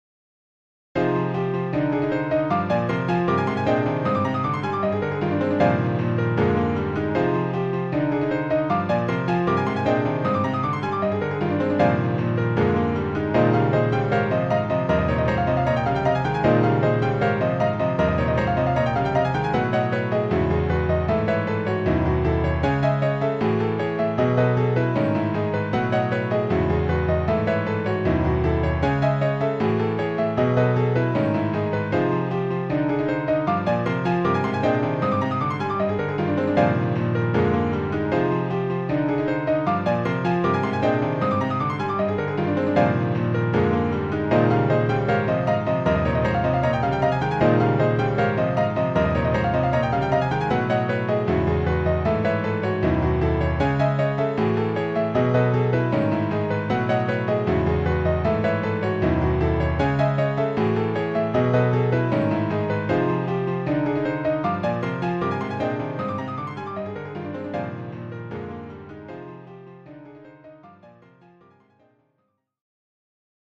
ジャンル：インストゥルメンタル